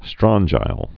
(strŏnjīl, -jəl)